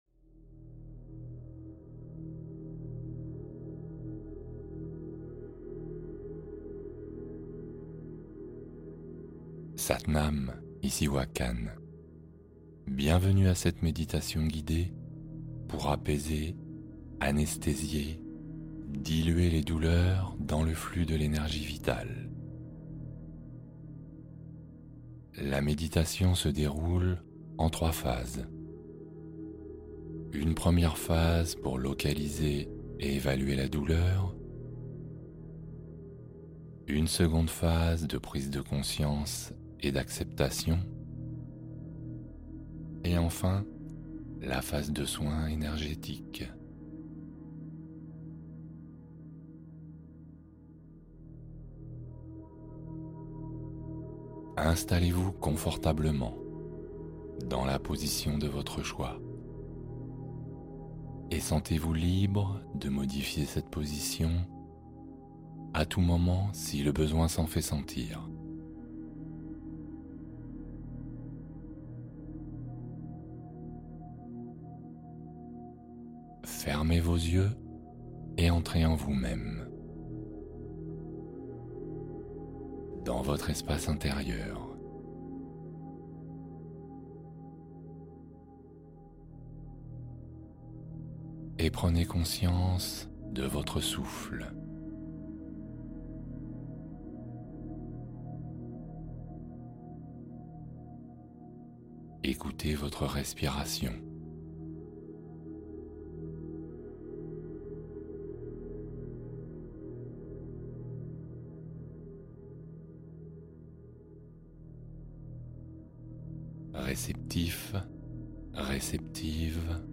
Confort Corporel : Apaiser les tensions par la méditation guidée